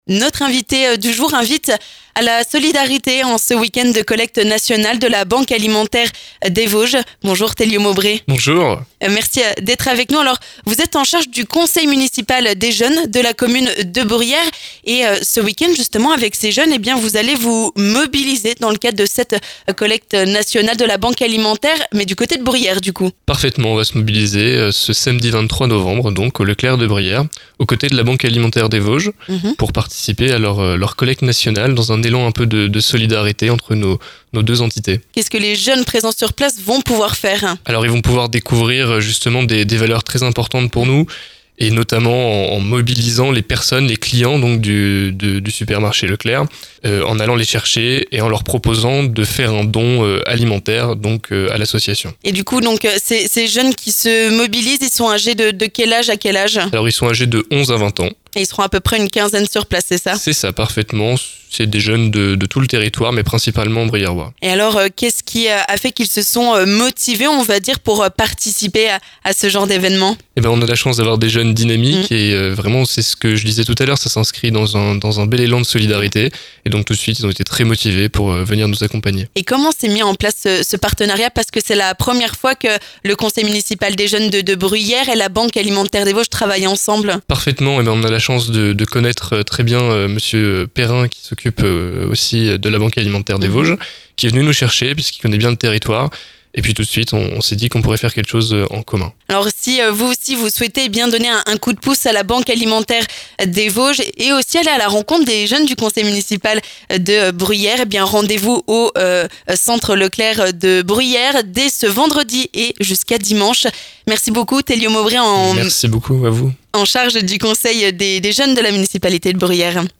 L'invité du jour